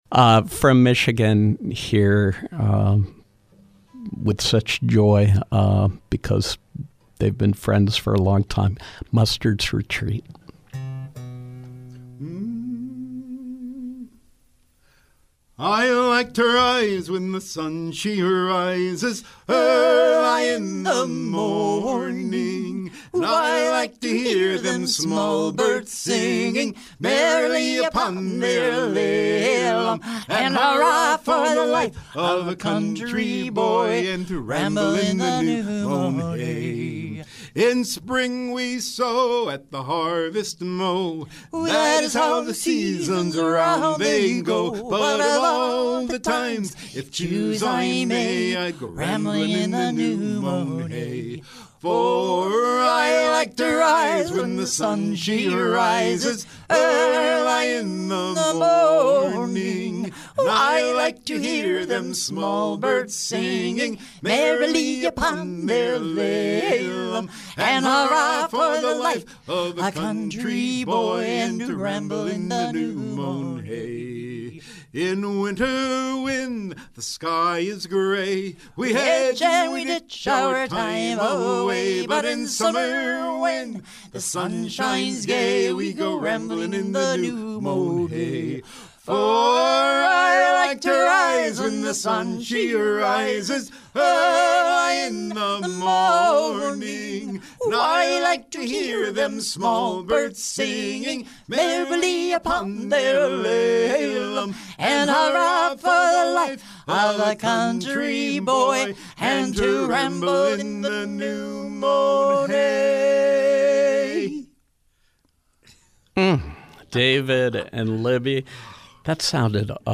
Michigan-based trio